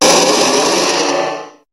Cri de Méga-Dardargnan dans Pokémon HOME.
Cri_0015_Méga_HOME.ogg